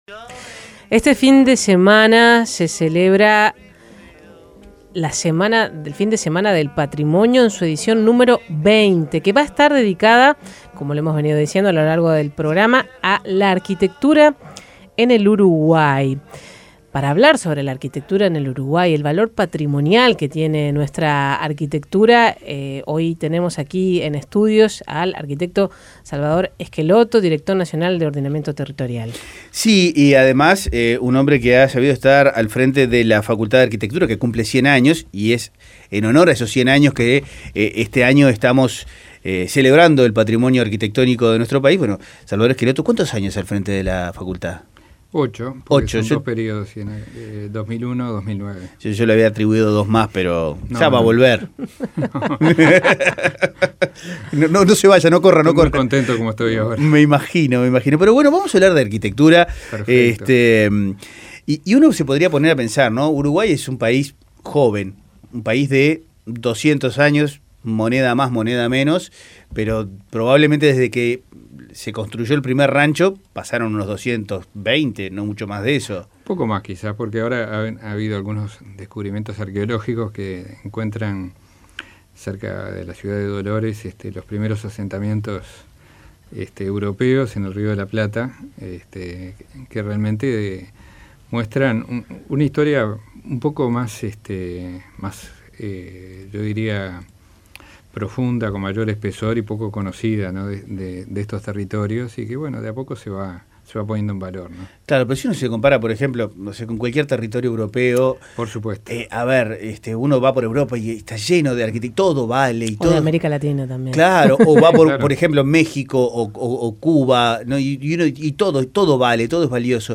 En la previa del fin de semana del Patrimonio, 810 Vivo conversó "Con Tiempo" con el arquitecto Salvador Schelotto, director nacional de ordenamiento territorial.
Escuche la entrevista Con Tiempo